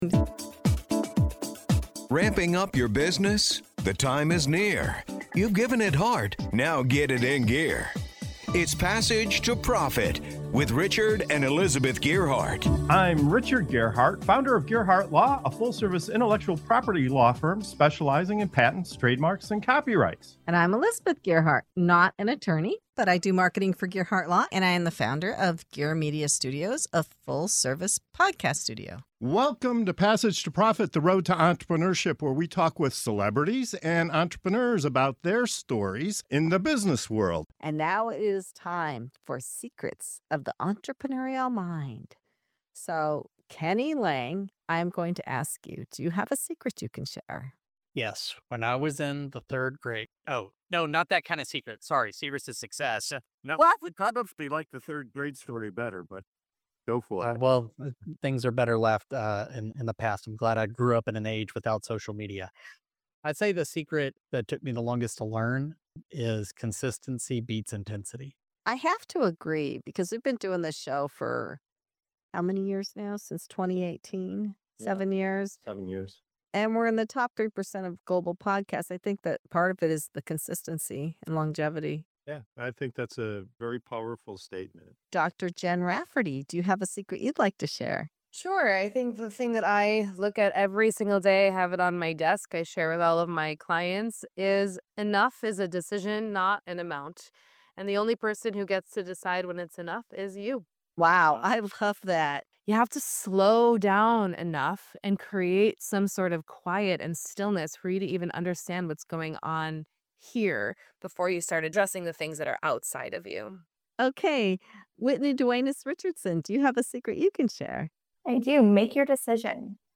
Discover the real "Secrets of the Entrepreneurial Mind" in this lively episode as our guests share their most powerful lessons for success.